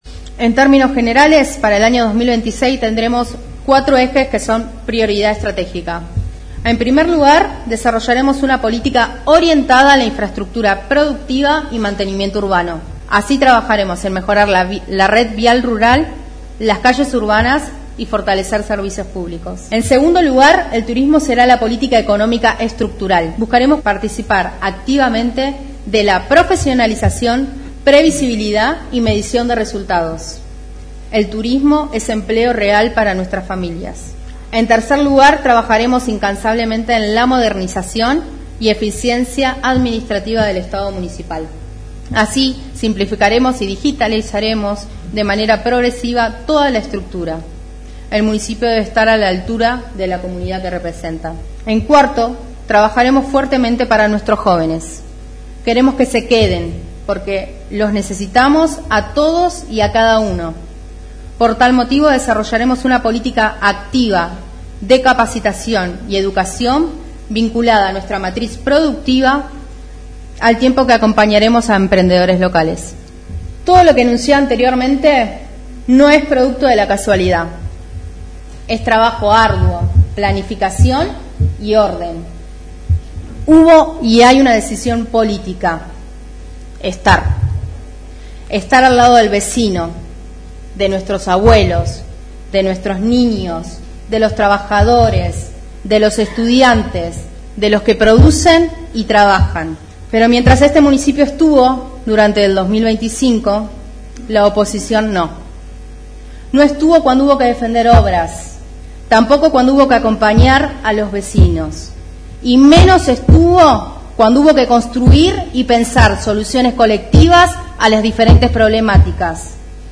En una colmada Sesión Inaugural del Honorable Concejo Deliberante, la Intendenta Municipal de Tornquist, Estefanía Bordoni, brindó su mensaje anual dejando en claro las prioridades para el presente ciclo.
La Intendenta Estefanía Bordoni dio inicio formal a las sesiones ordinarias del HCD con un discurso marcado por la cercanía y la gestión. Durante su alocución, la mandataria definió cuatro pilares fundamentales que guiarán el presupuesto y la acción municipal durante el 2026: Salud, Turismo Sustentable, Vivienda y Educación vinculada a la producción.